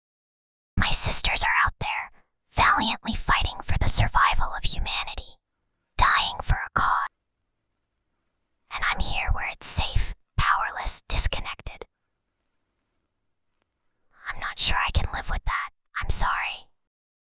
File:Whispering Girl 19.mp3
Whispering_Girl_19.mp3